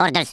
Worms speechbanks
orders.wav